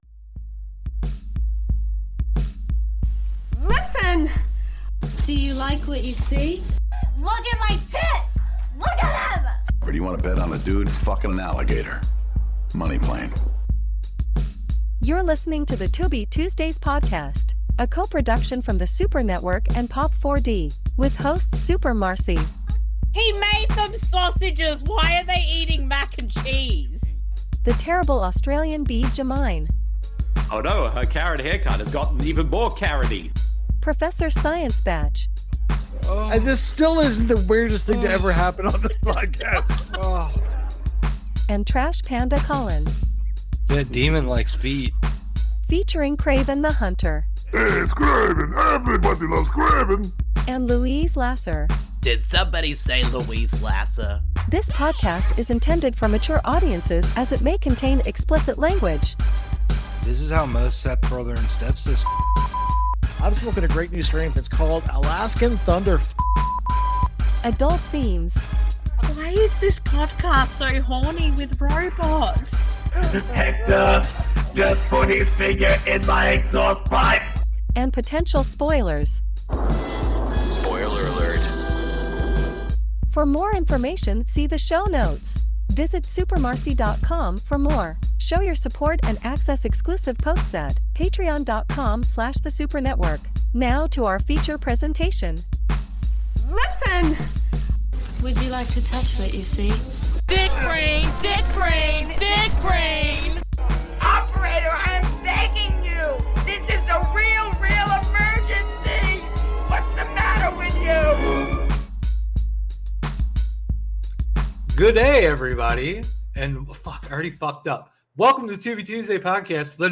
This podcast series is focused on discovering and doing commentaries/watch a longs for films found on the free streaming service Tubi, at TubiTV
Welcome back to The Tubi Tuesdays Podcast, the number one Tubi related podcast that’s hosted by two Australians, one Canadian and one American!